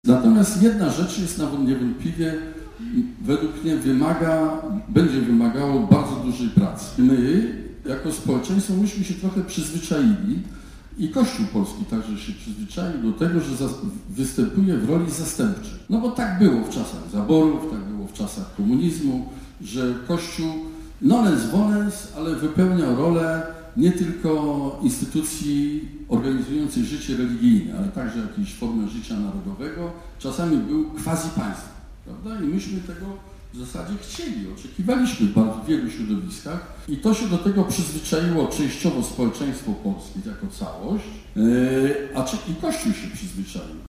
Wczoraj z wizytą w Zielonej Górze przebywał były prezydent Bronisław Komorowski. Podczas otwartego spotkania z mieszkańcami, na które przybyło kilkadziesiąt osób, Bronisław Komorowski poruszył kwestię roli Kościoła w życiu społecznym naszego kraju.